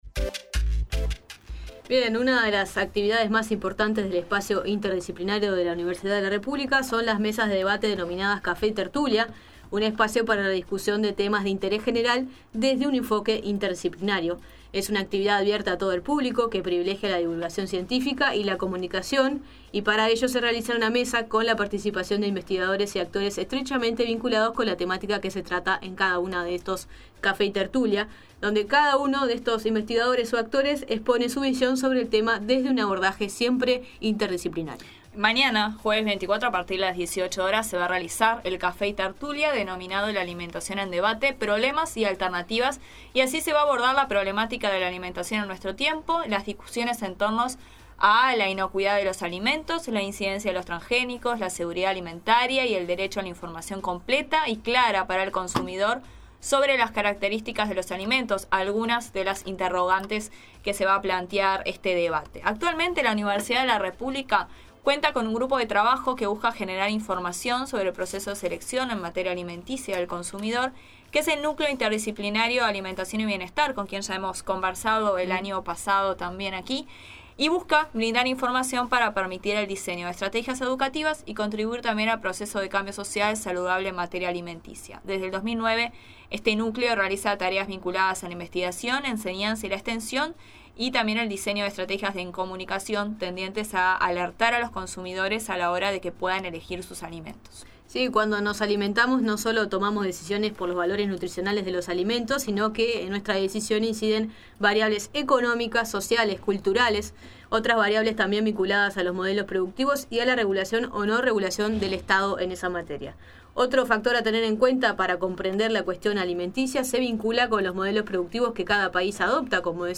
La Nueva Mañana conversó en vivo con uno de los panelistas de esta mesa